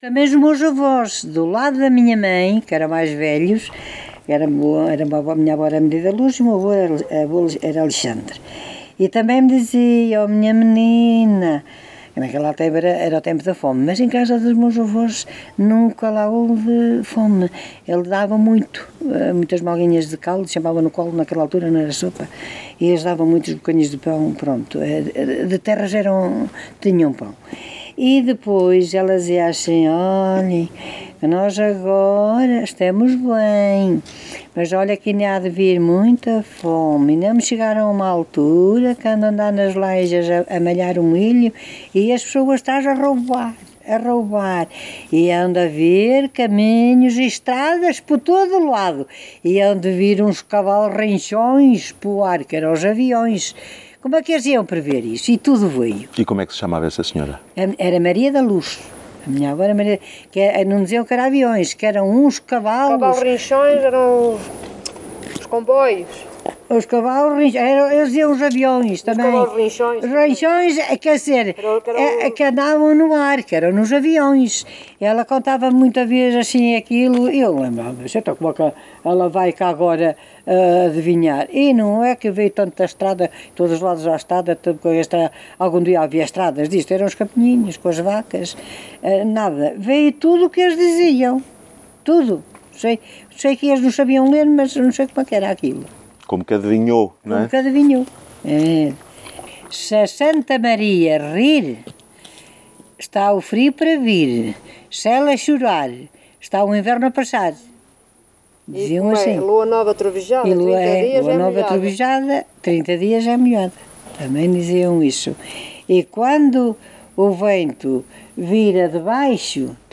Tipo de Prática: Inquérito Etnográfico
Local: Várzea de Calde